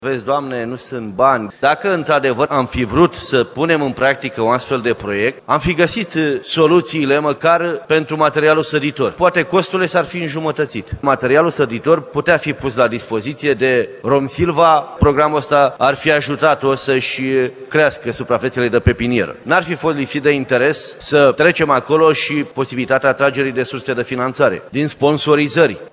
Deputatul PNL, Tinel Gheorghe a spus că se pot găsi soluții pentru plantarea copacilor.